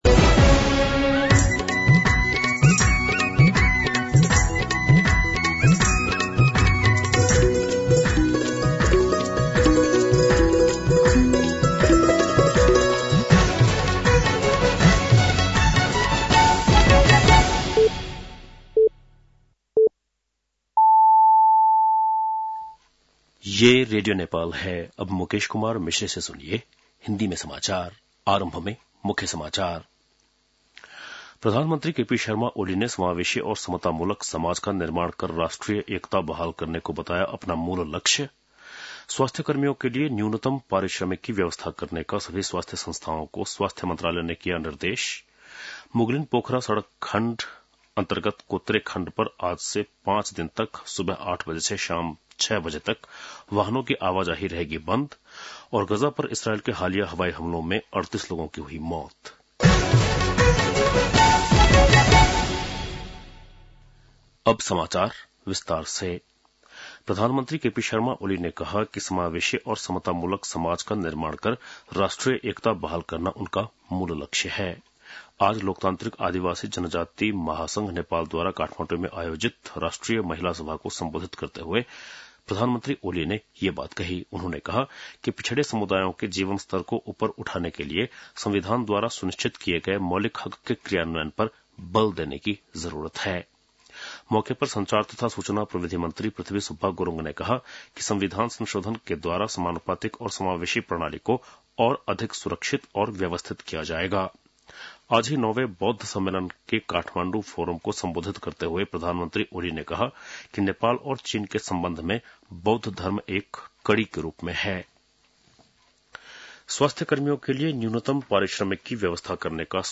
बेलुकी १० बजेको हिन्दी समाचार : २९ मंसिर , २०८१
10-PM-Hindi-News-8-28.mp3